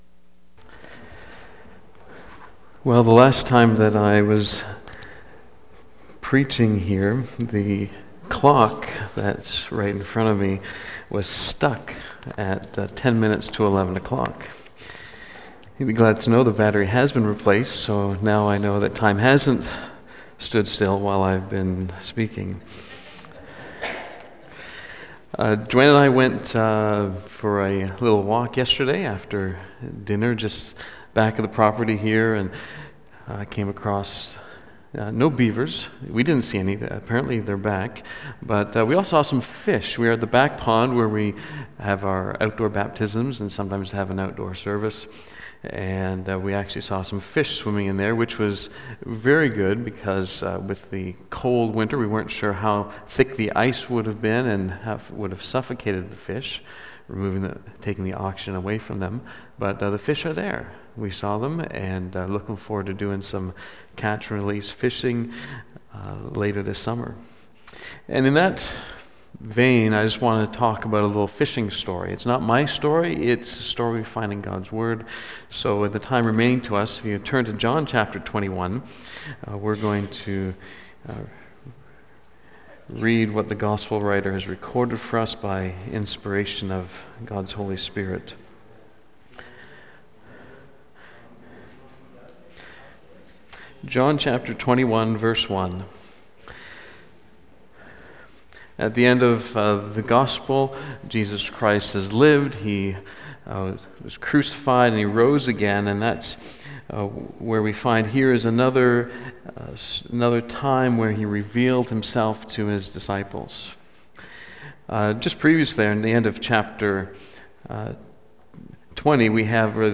2014 John 21 BACK TO SERMON LIST Preacher